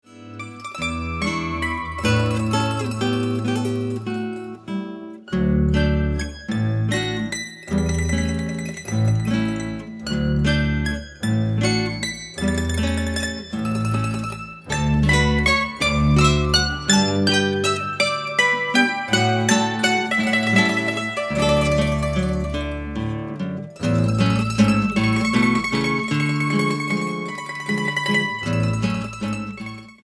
vals lento.